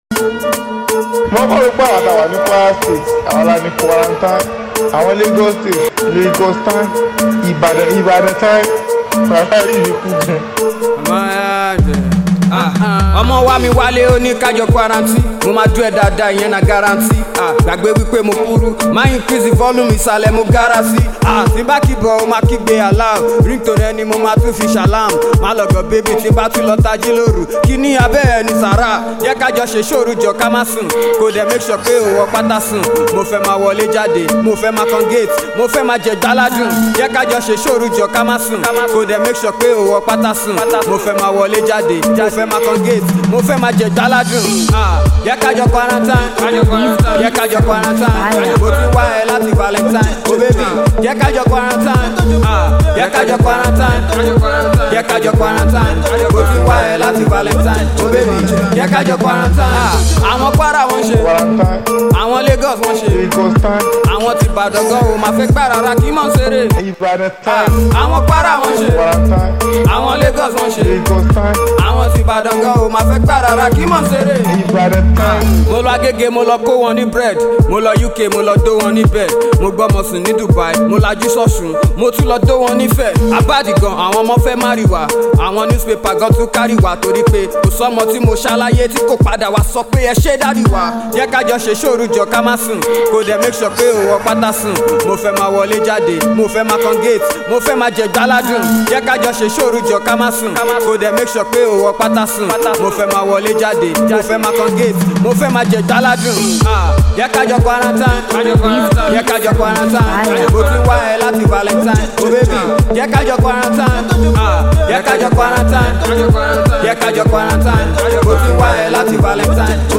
Uber-talented indigenous rapper
freestyle